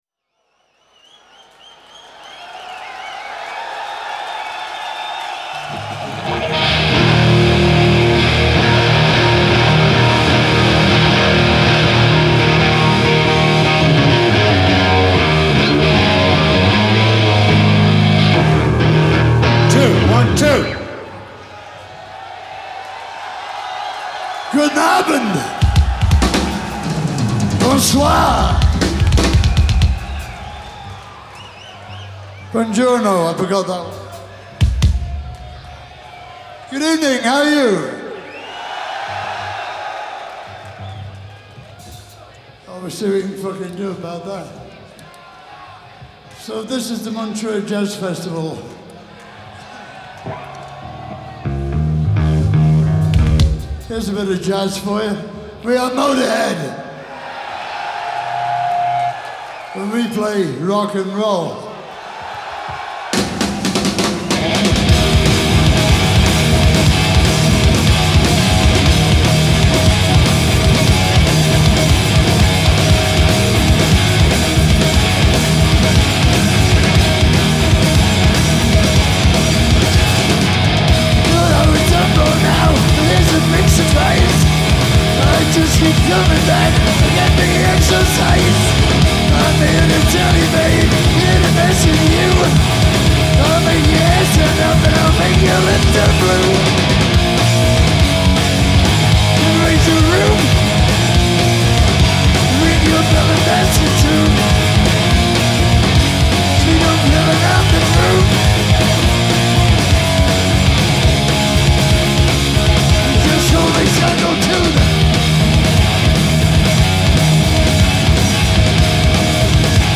Hi-Res Stereo
Genre : Rock